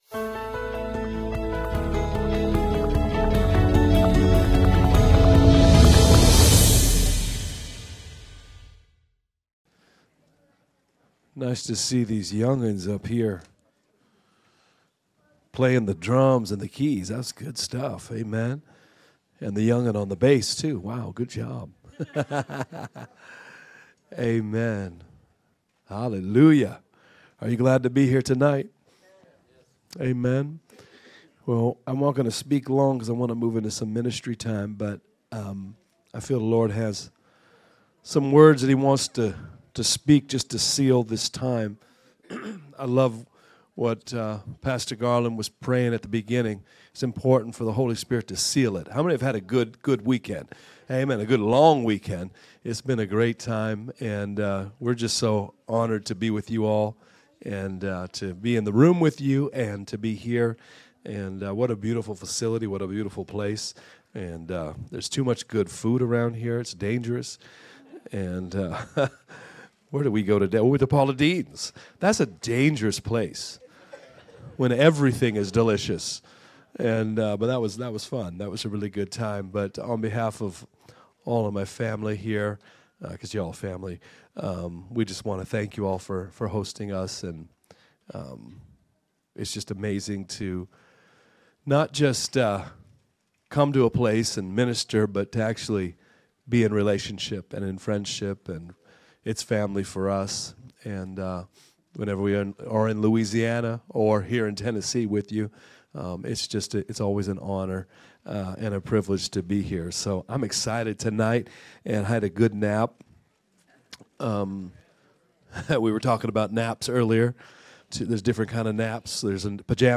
Sermons | Praise Church of New Orleans